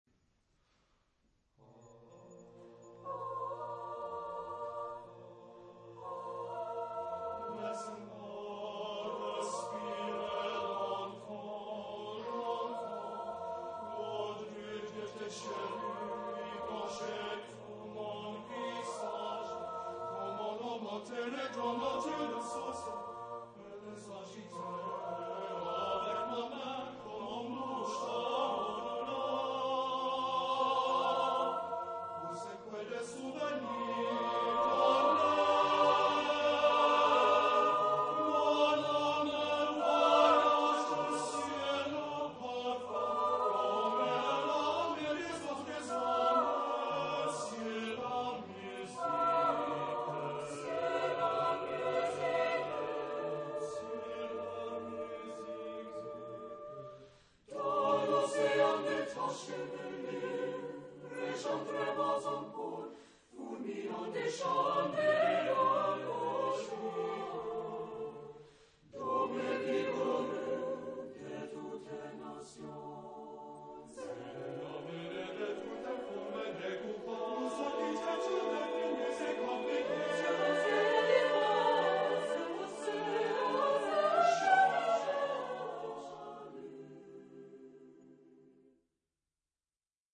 Tipo de formación coral: SAATBB  (6 voces Coro mixto )
Tonalidad : modal